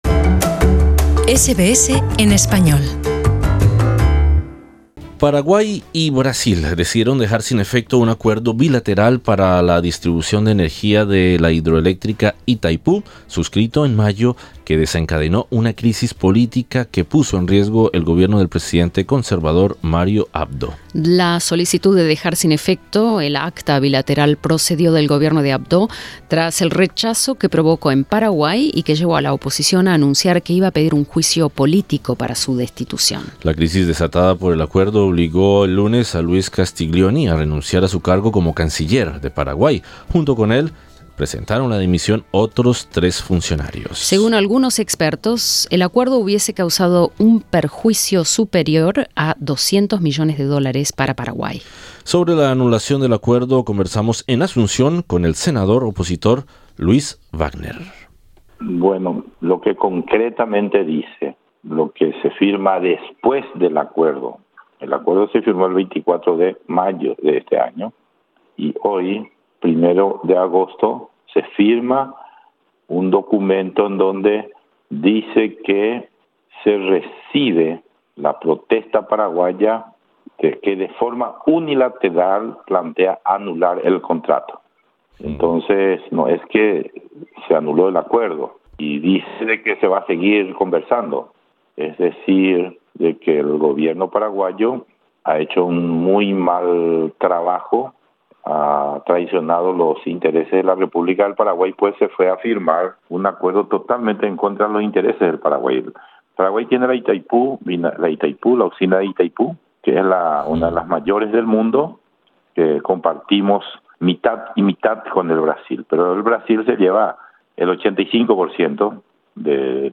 Sobre la anulación del acuerdo, conversamos en Asunción con el Senador opositor Luis Wagner.